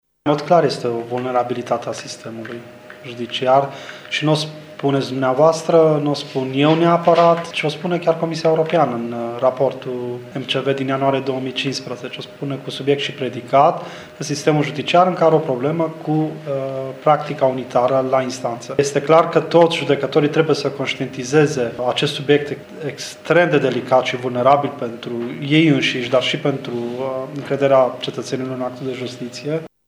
Prezent la şedinţa de bilanţ a Curţii de Apel Tîrgu-Mureş judecătorul Horaţius Dumbravă, membru al Consiliului Superior al Magistraturii, a spus că toţi judecătorii ar trebui să conştietizeze acest lucru: